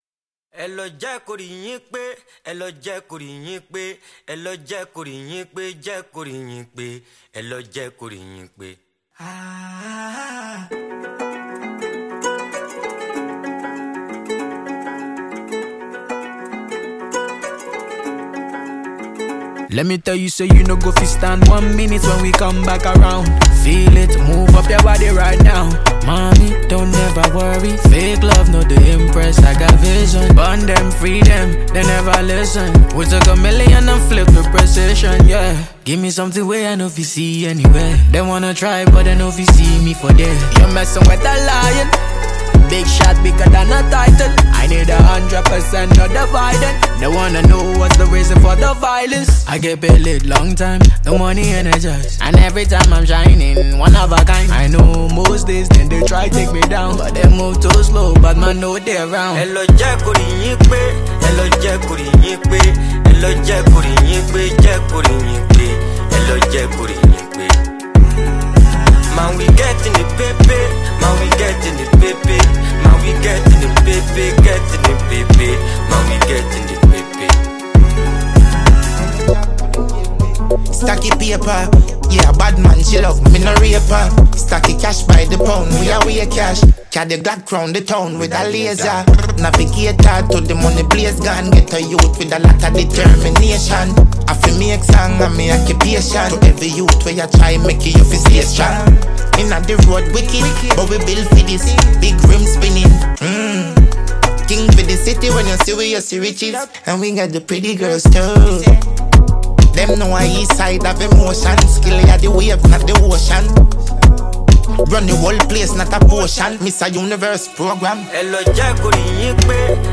a noteworthy Nigerian afrobeat singer
With its infectious beat and captivating vocals